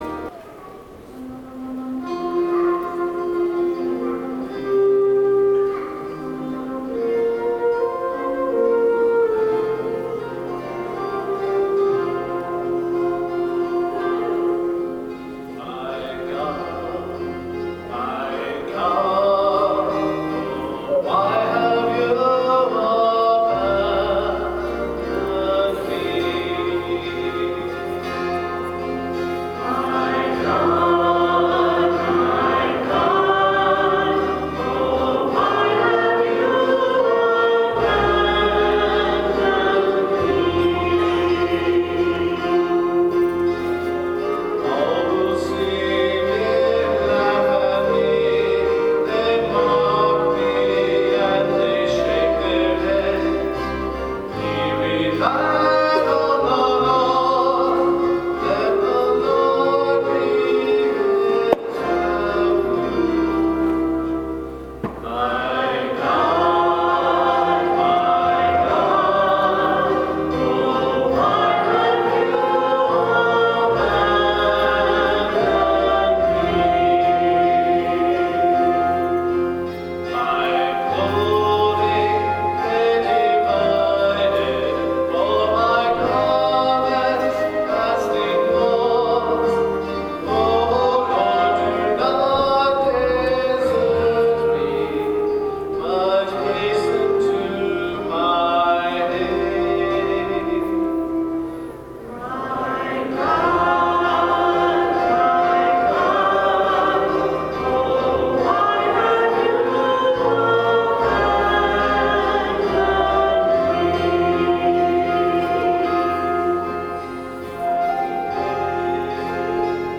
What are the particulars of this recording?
Music from the 10:30 Mass on the 1st Sunday in Lent, March 24, 2013: On the 3rd verse of the Psalm